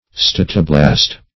Statoblast \Stat"o*blast\ (st[a^]t"[-o]*bl[a^]st), n. [Gr.